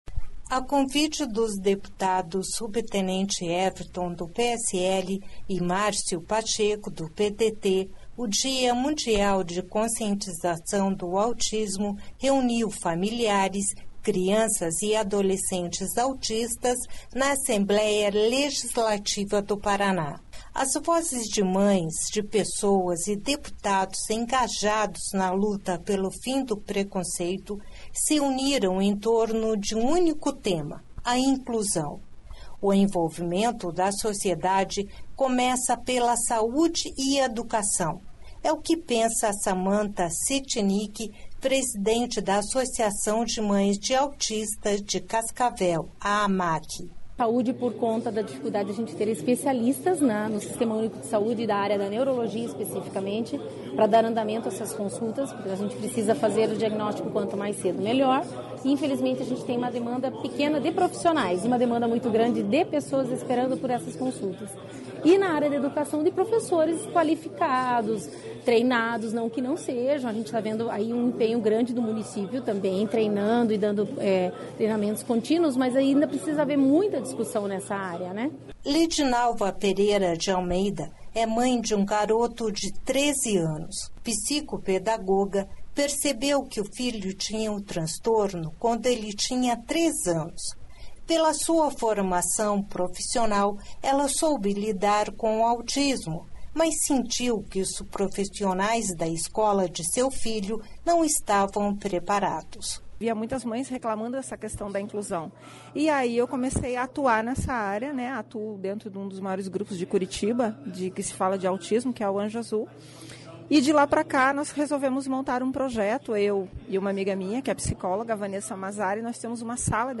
As vozes de mães, de pessoas e deputados engajados na luta pelo fim do preconceito, se uniram em torno de um único tema: a inclusão.
Sonora Márcio Pacheco
Sonora deputado Márcio Pacheco